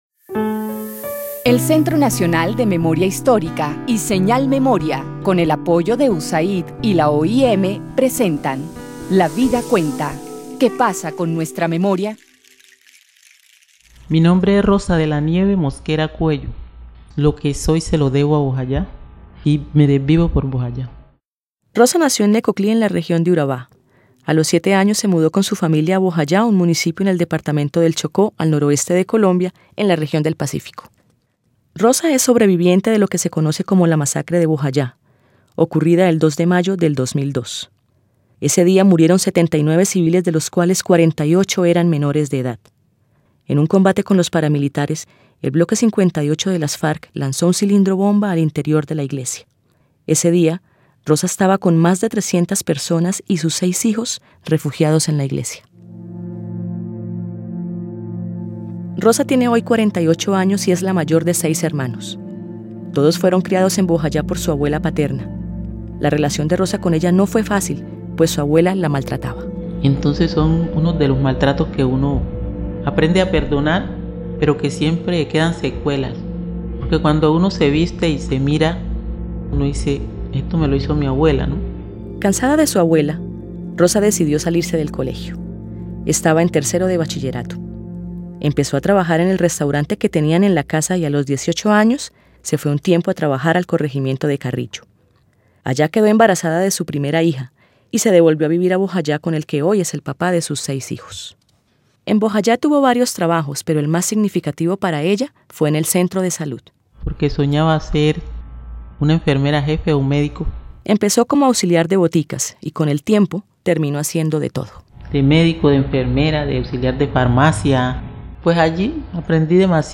(tomado de la fuente) Audiencia (dcterms:audience) General Descripción (dcterms:description) Serie radial basada en el informe ¡Basta ya! Colombia: memorias de guerra y dignidad.